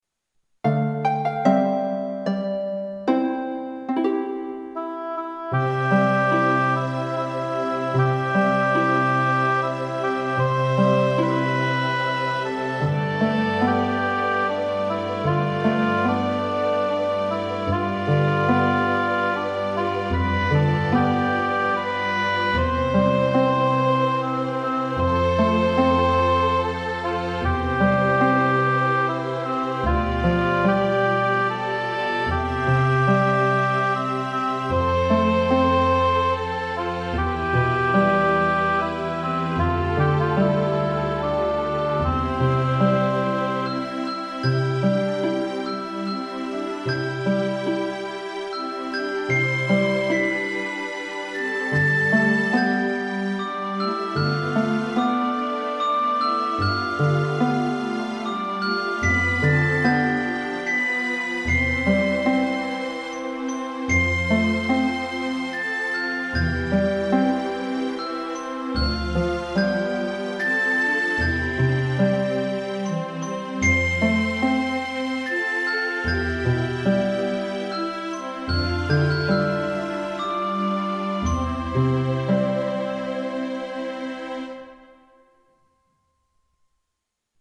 Una Ninna nanna davvero speciale. Composta da J. Brahms, rivela una sorprendente semplicità melodica e una straordinaria dolcezza.
La proponiamo in versione didattica per flauto.